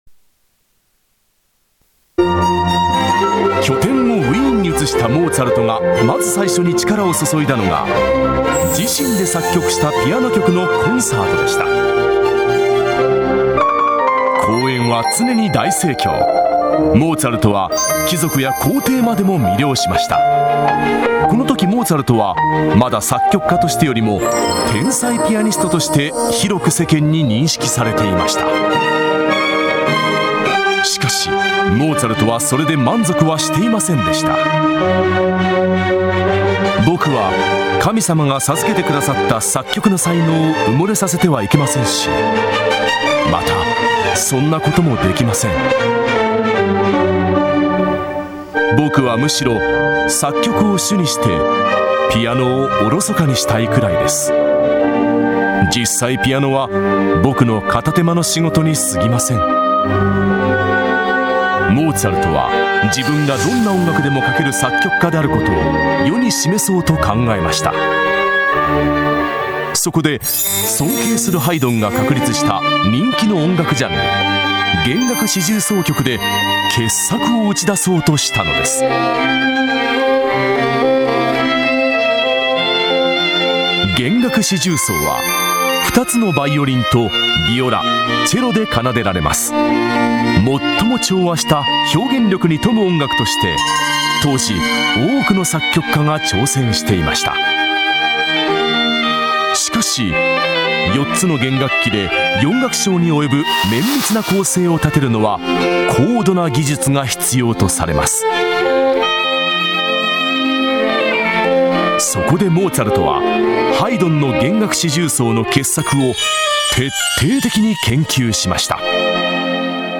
65_1 弦楽四重奏曲　「不協和音」第1楽章 　※ﾅﾚｰｼｮﾝ入り
(ﾓｰﾂｱﾙﾄ作曲　ｳﾞｫｰﾁｪ弦楽四重奏団　5:05)
028_gengaku4.mp3